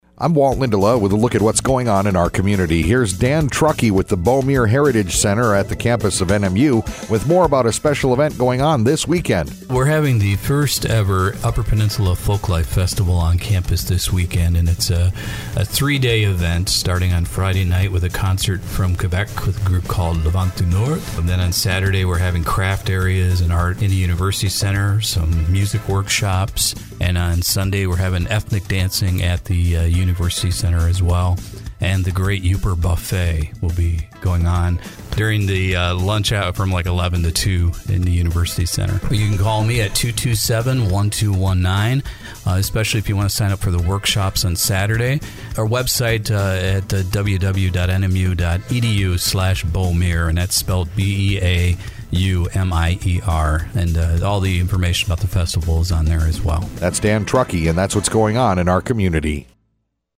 Old Interviews Archive